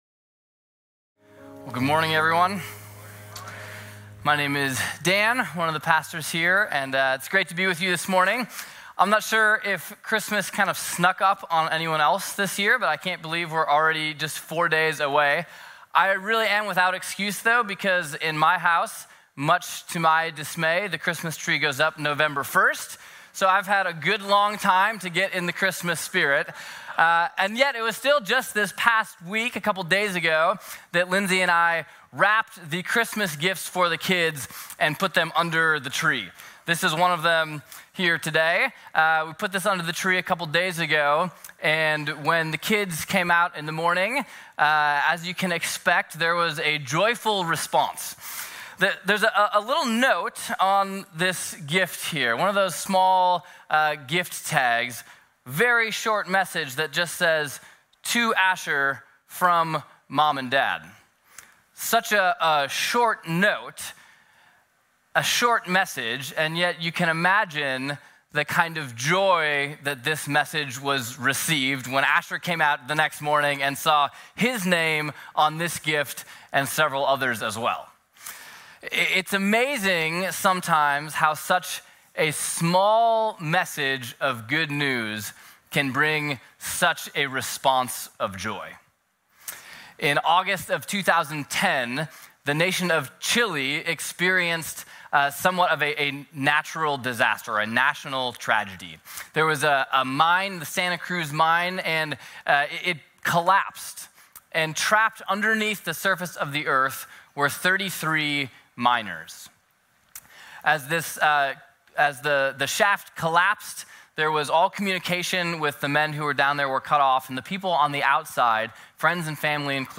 A message from the series "Love Came Down."